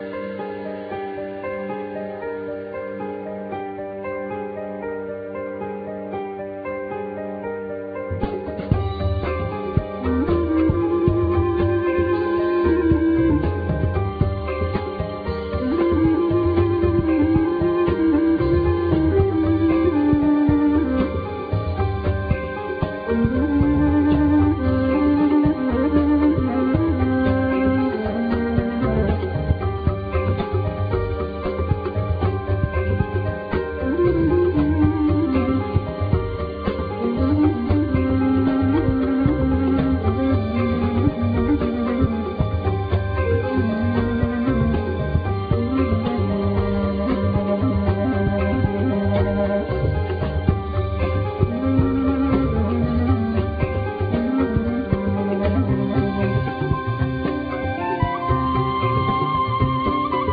Shevi,Duduk,Zourna,Clarinet,Bass recorder,Vocals
Piano,Vocals,Synthsizer
Drums,Tabla,Bendir,Tuberleki,Nada drums,Bells,Triangle